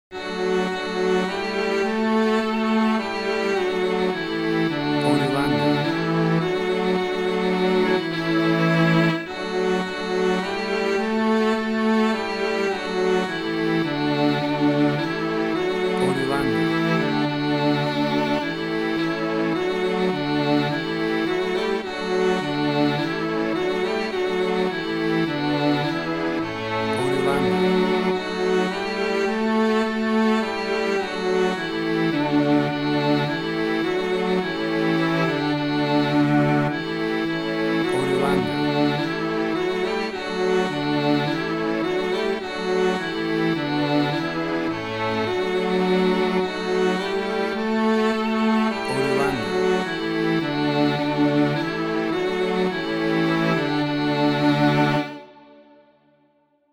Tempo (BPM): 107